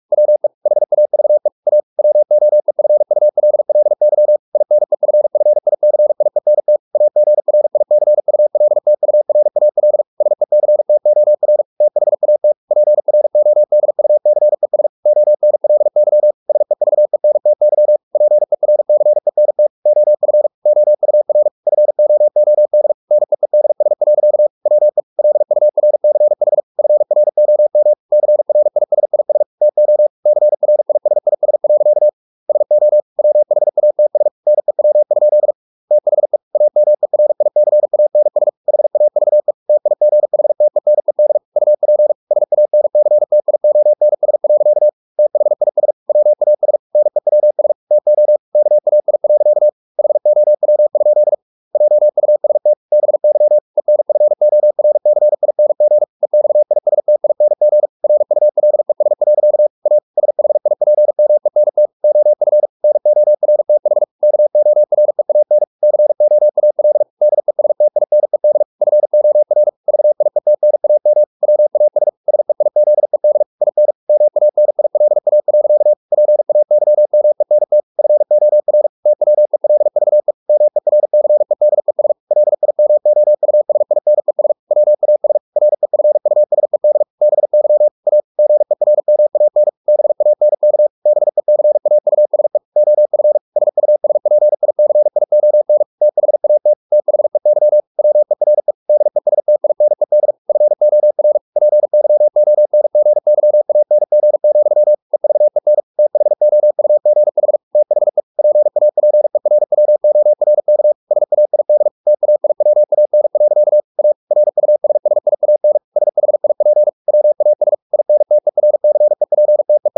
Never 45wpm | CW med Gnister